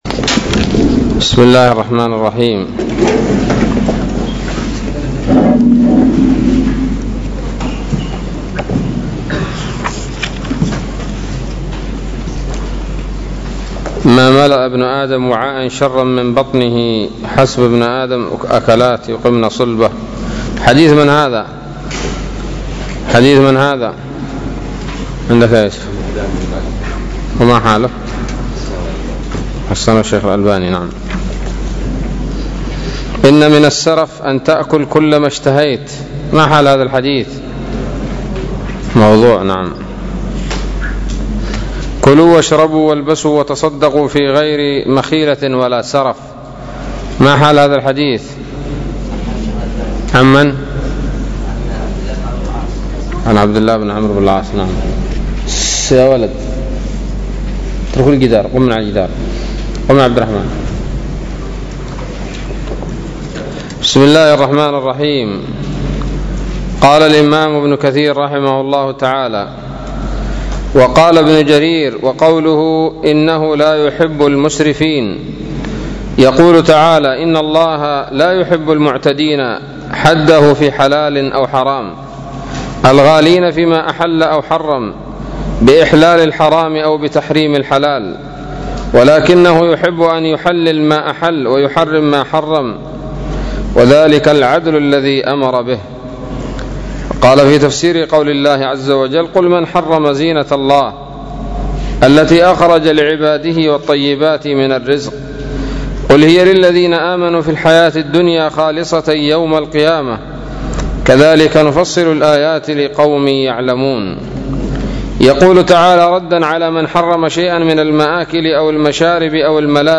الدرس الثالث عشر من سورة الأعراف من تفسير ابن كثير رحمه الله تعالى